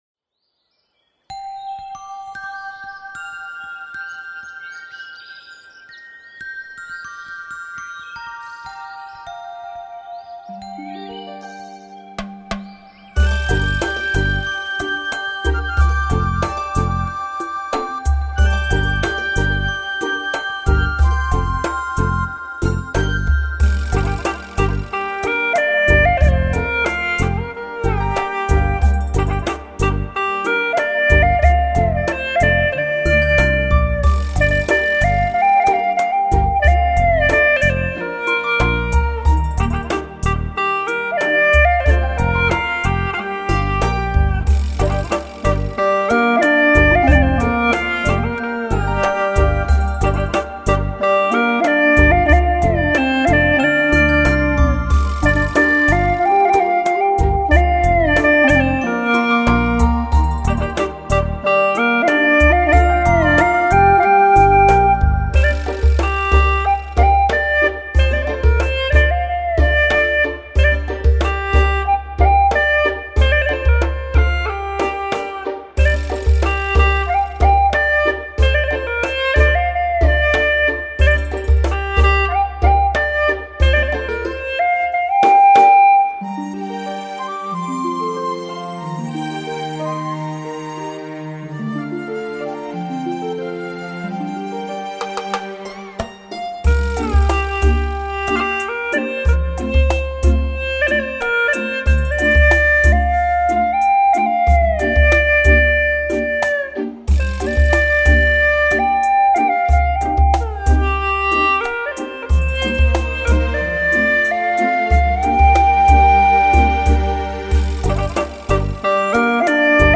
调式 : 降B 曲类 : 独奏
在悦耳的葫芦丝音乐中，我们仿佛看到了五颜六色的蝴蝶，它们姿态轻盈萦绕，在花丛中翩翩起舞，孕育着我们对美好生活的向往。
此曲欢快、活泼,节奏感强！